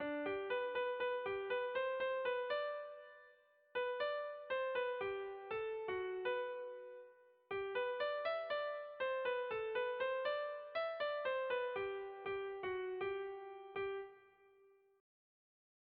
A-B